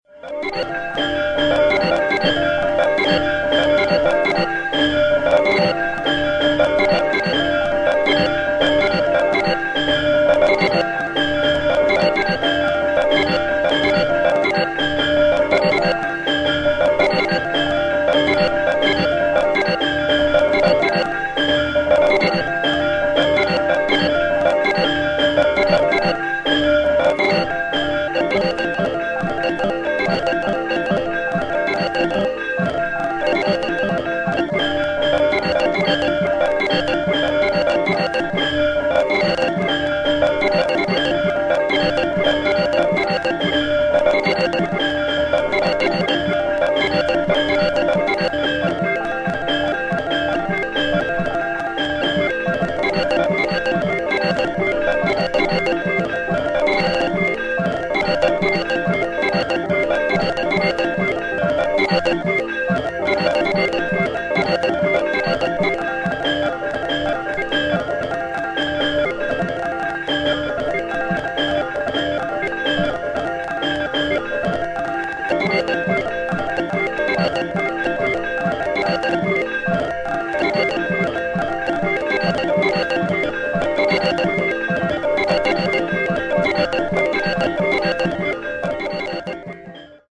ガムランのサンプリング音が徐々に変化していく